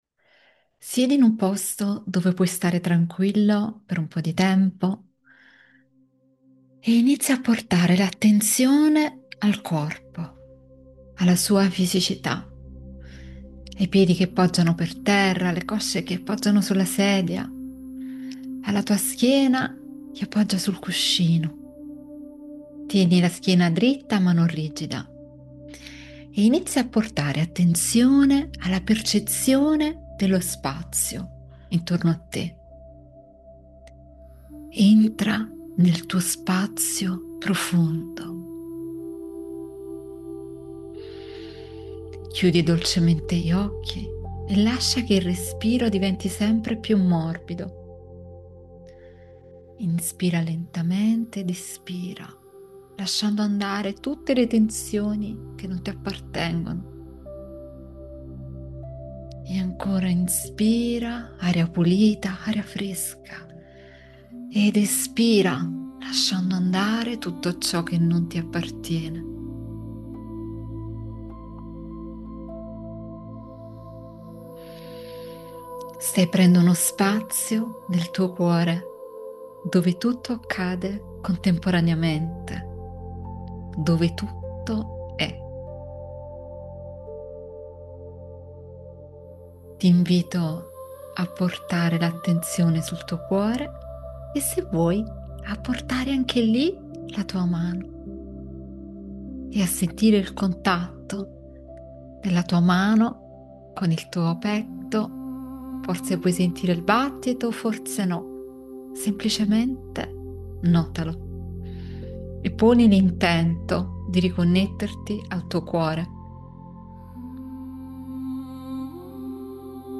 Meditazione guidata – Incontro con il Bambino Interiore | Riconnessione profonda
Attraverso una guida dolce e sicura, sarai accompagnato a tornare in uno spazio del tuo passato per incontrare il tuo bambino interiore: la parte più autentica, sensibile e vera di te, quella che custodisce i tuoi bisogni profondi, ma anche la memoria dei tuoi sogni e della tua essenza.